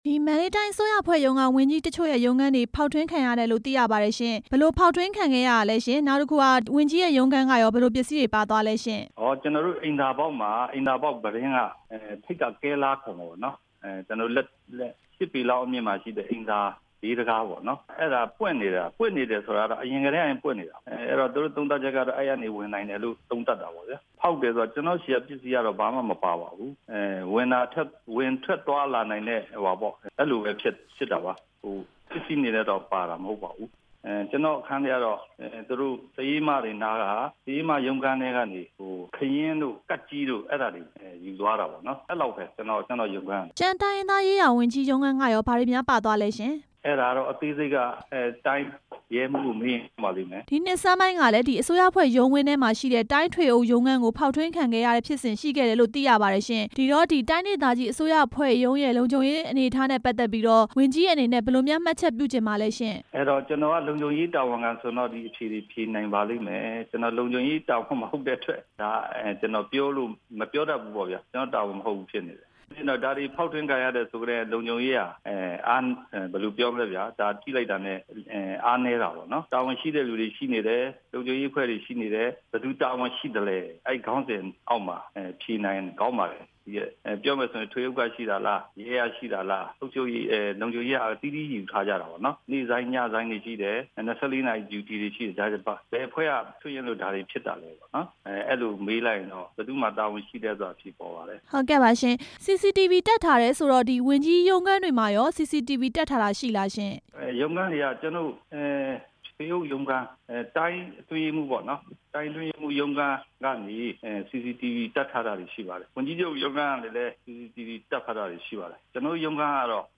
မန္တလေးအစိုးရဝန်ကြီးရုံးခန်း ၂ ခုဖောက်ထွင်းခံရမှု မေးမြန်းချက်